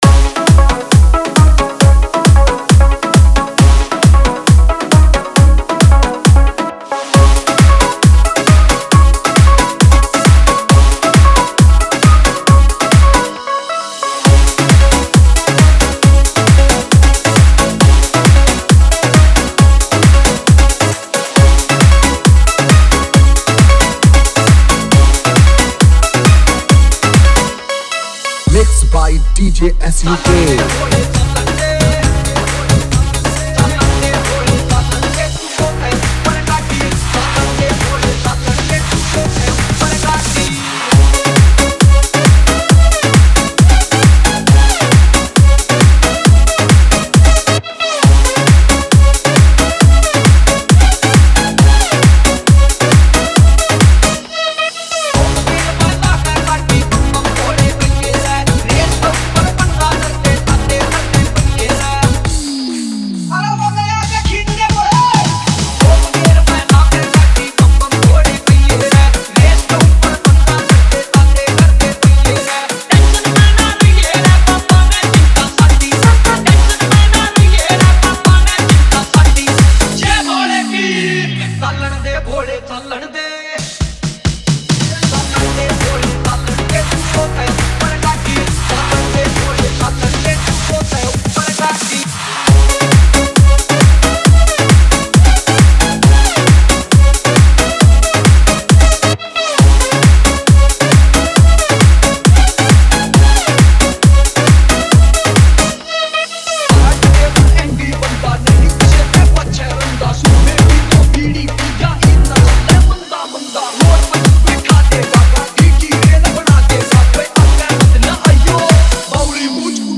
Bhakti DJ Remix Songs